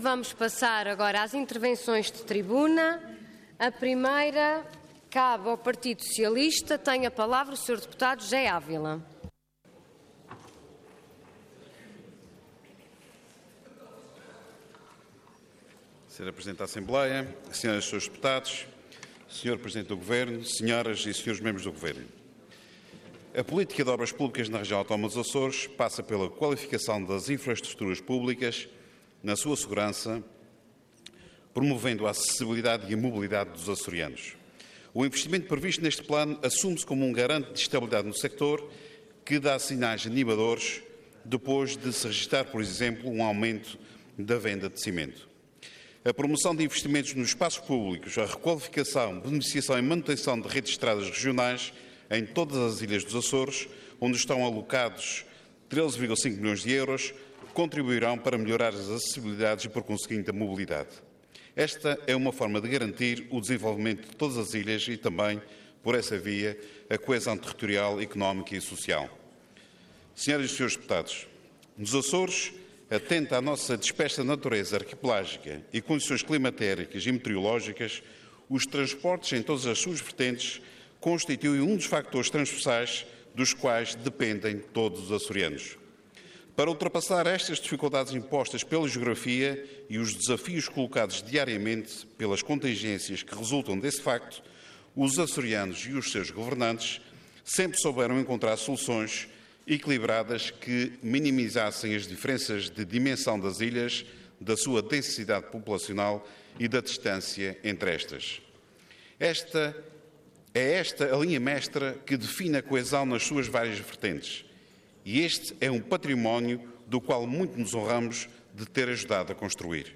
Intervenção Intervenção de Tribuna Orador José Ávila Cargo Deputado Entidade PS